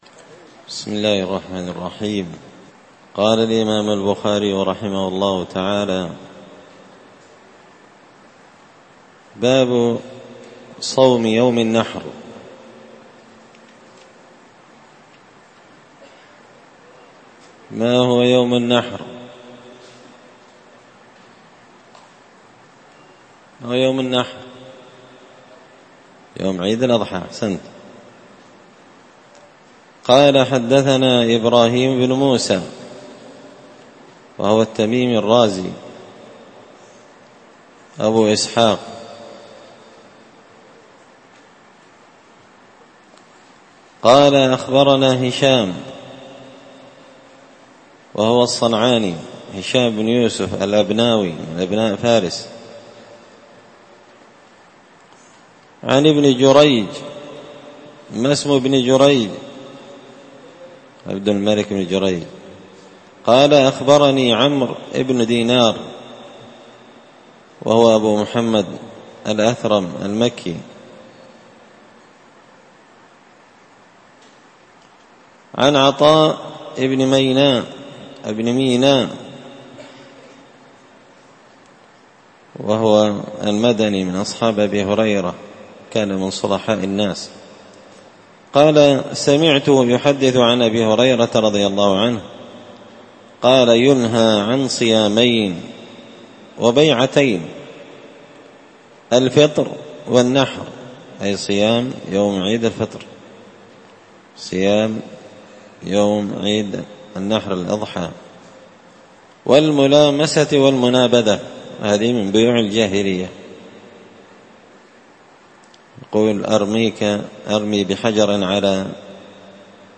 الدرس الثاني والخمسون (52) باب صوم يوم الأضحى